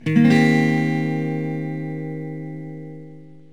1 channel
Cm7.mp3